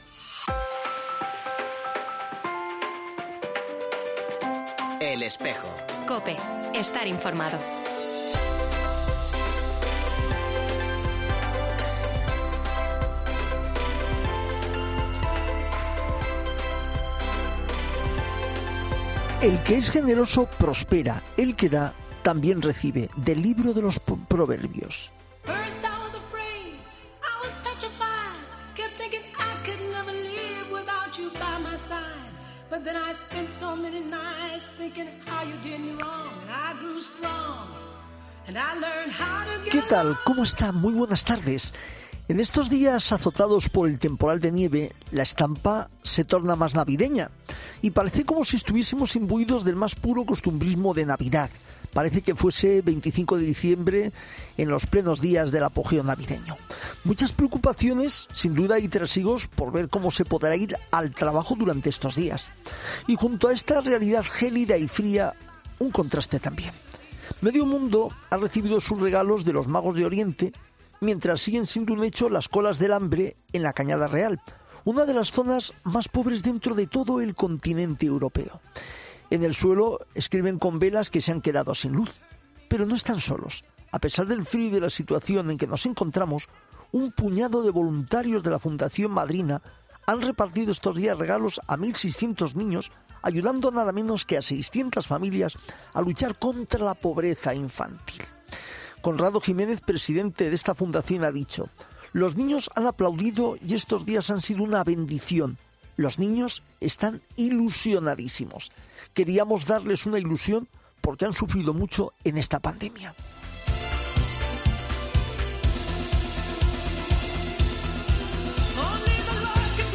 entrevista e Hispanoamérica.